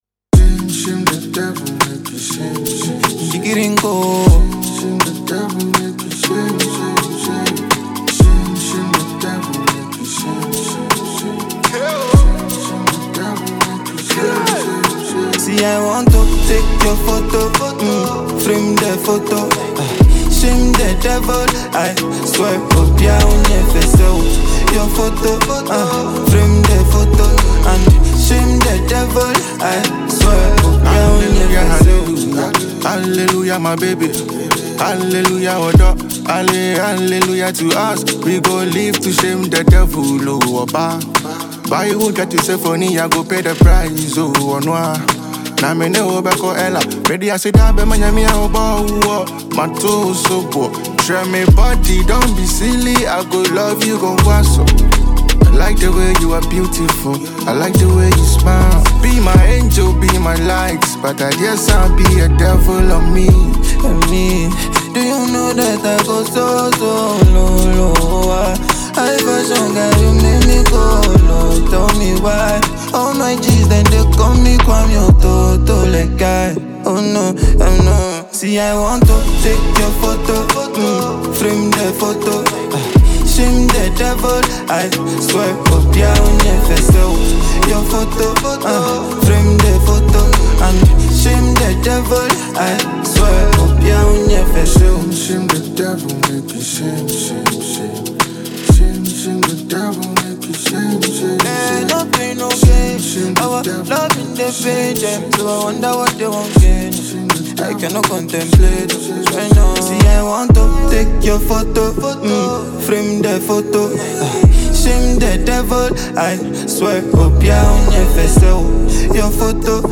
a Ghanaian trapper
Gh singer